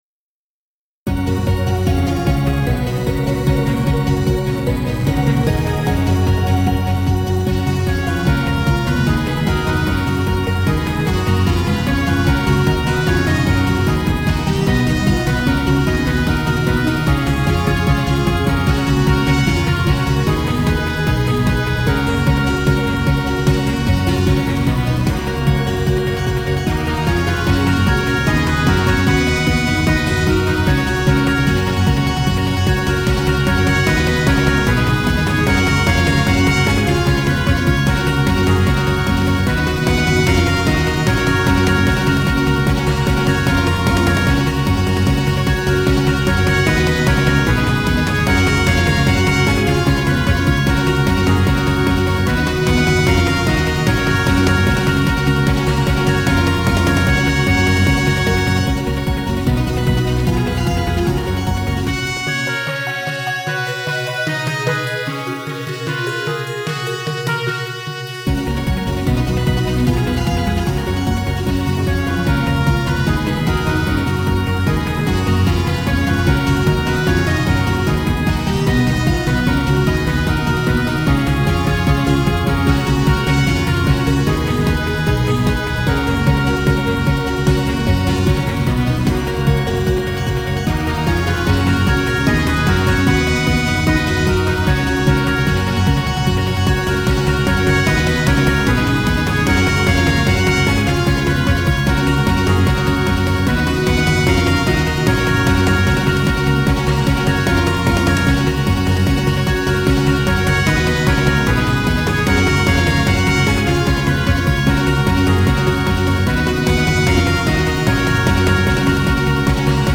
嬰ヘ長調